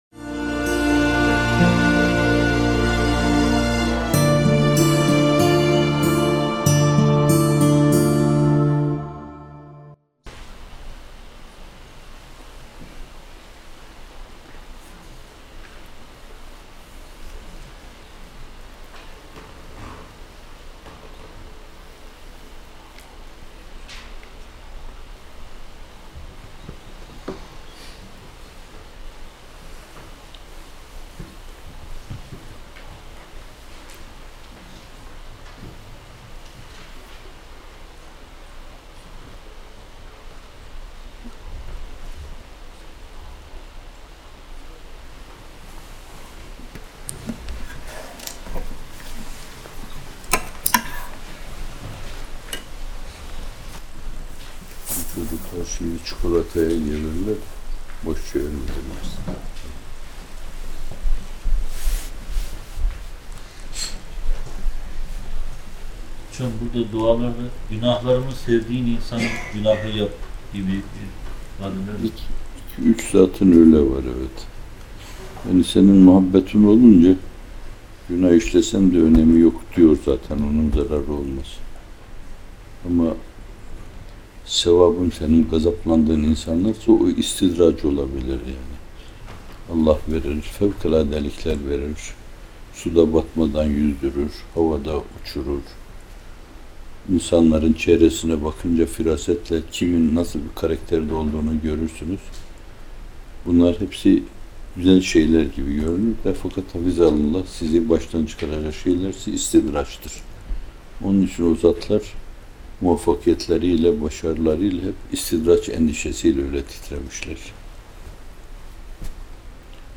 Muhterem Fethullah Gülen Hocaefendi’nin, 30 Haziran 2015 tarihinde, mukabele sonrası iftar vaktini beklerken kaydedilen değerli anları.